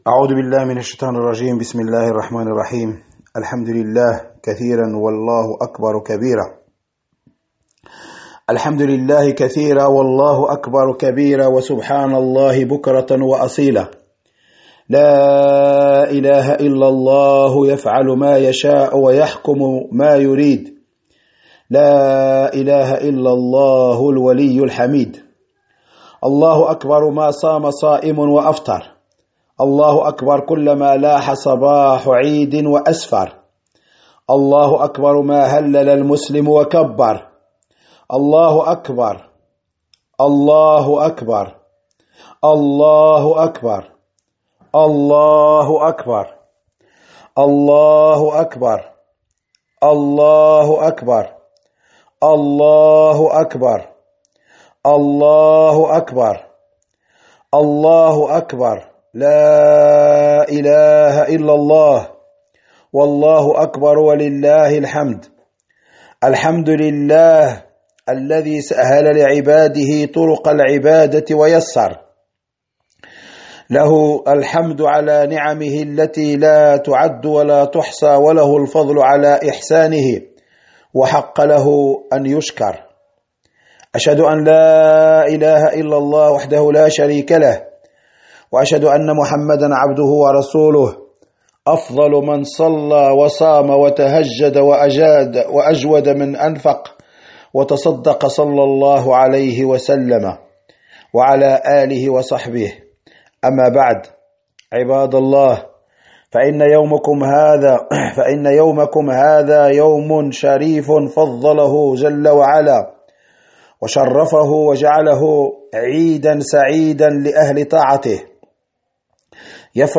خطبة عيد الفطر لعام 1439 هـ